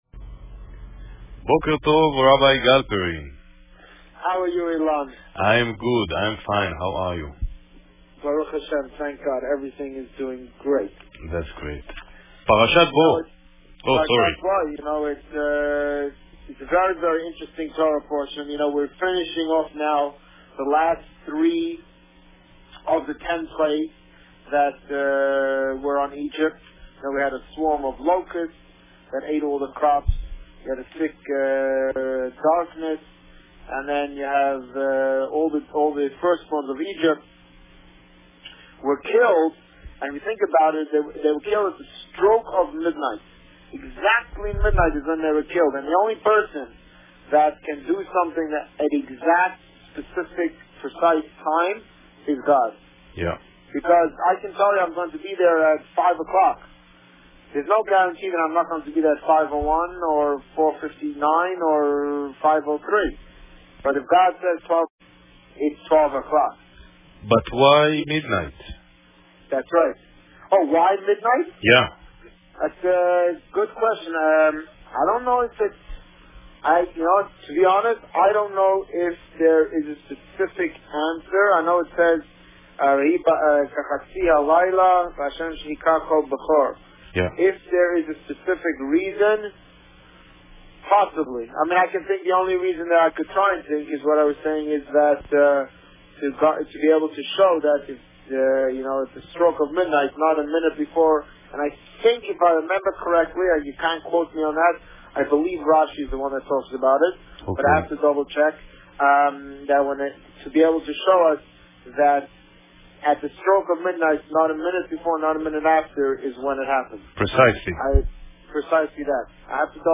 The Rabbi on Radio
On January 6, 2011, the Rabbi spoke about Parsha Bo. Due to the scheduling of the interview, the radio station's recording spanned two time slots, so they split the Boker Tov interview into two pieces. We have done our best to splice the recordings together.